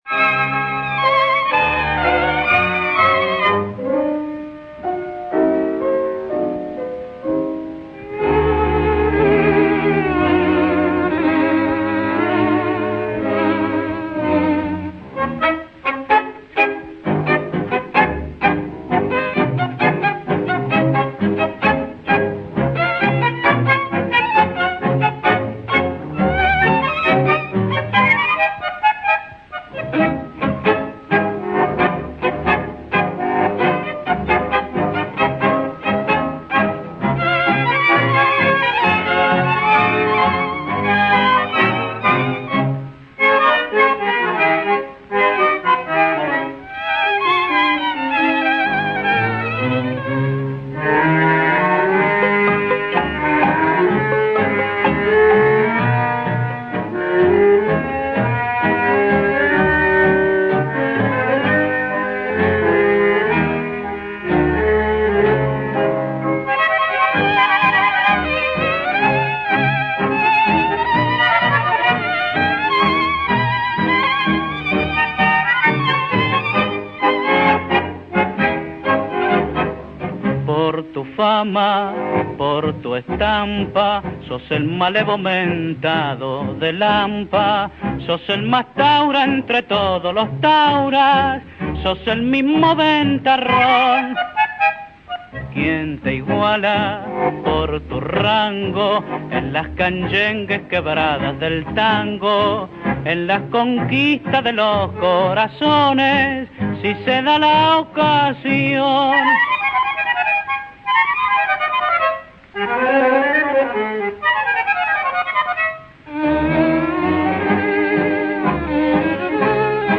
En premier, je vous propose la version de danse.
violoniste